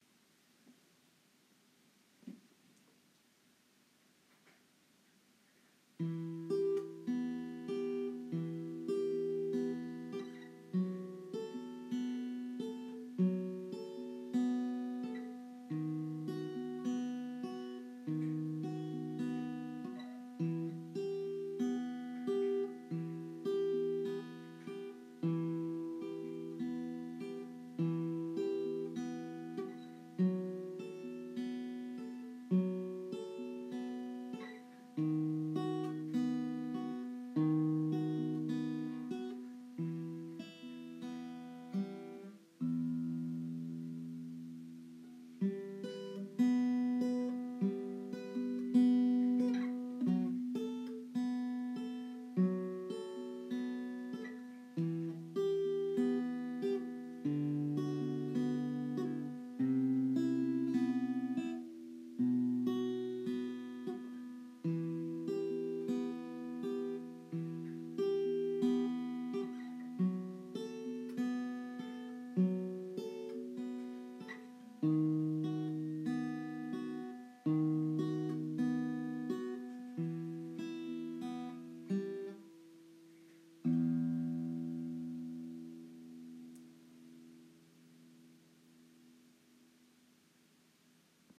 Vill du sen lyssna till ett stycke jag spelat in är du välkommen att trycka på spela-knappen nedan. Det är min resa i att lära mig att spela efter noter. Stycket är kort och heter ”Stillhet”: